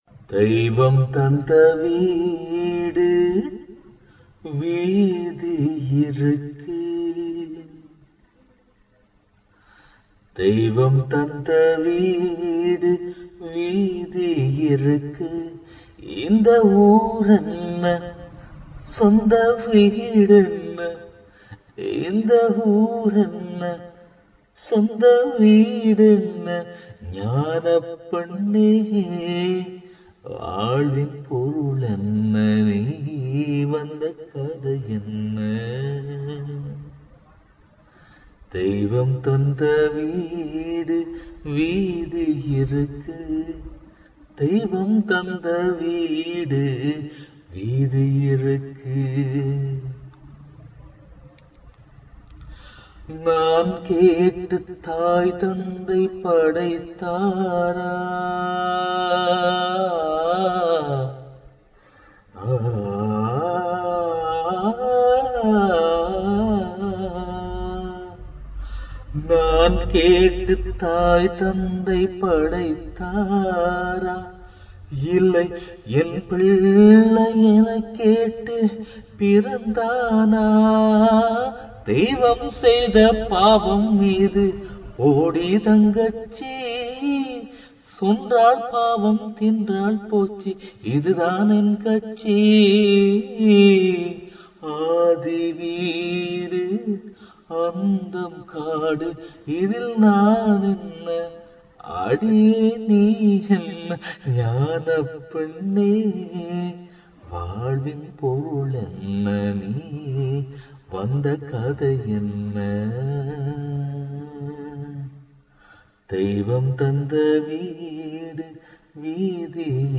I could not sing full song